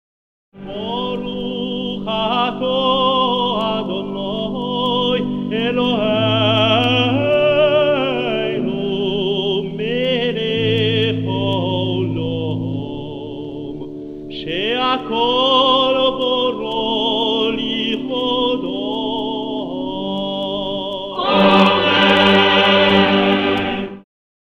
2e bénédiction (rite ashkenaze
accompagné à l’orgue